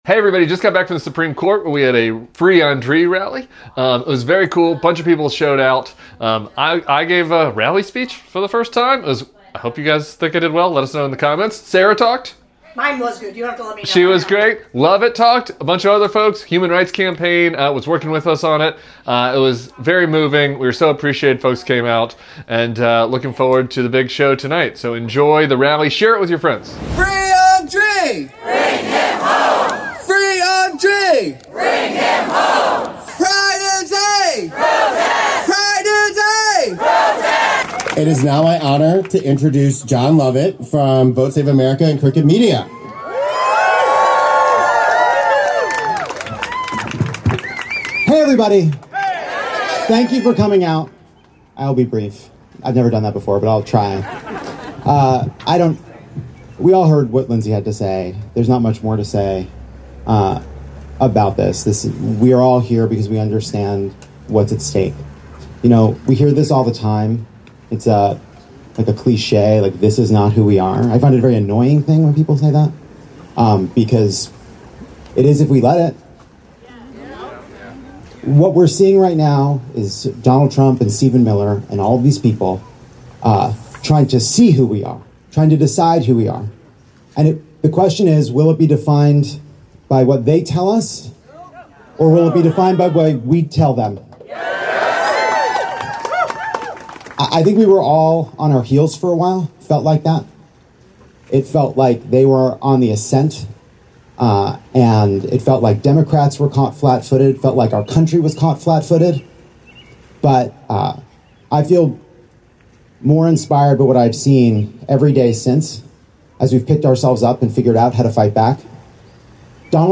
on the steps of the Supreme Court